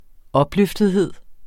Udtale [ ˈʌbløfdeðˌheðˀ ]